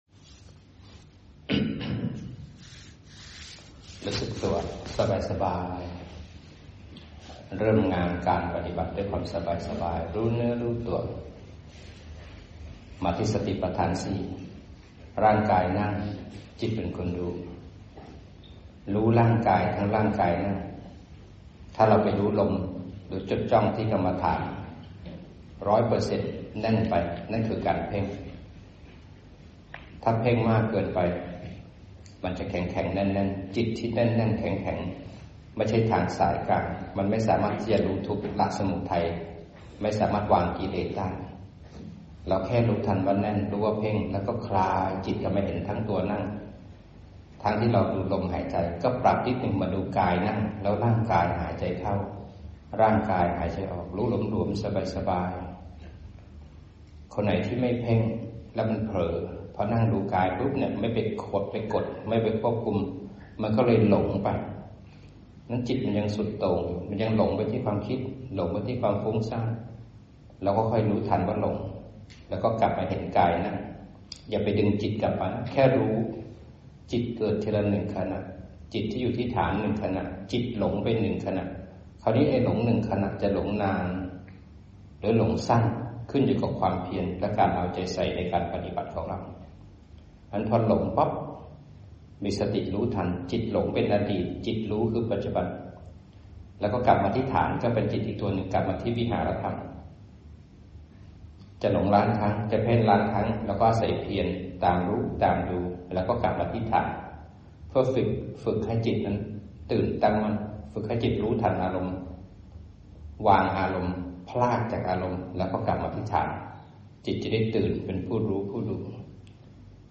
อจ.นำจิตปฏิบัติ สติสมาธิและปัญญา